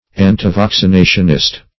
Antivaccinationist \An`ti*vac`ci*na"tion*ist\, n. An antivaccinist.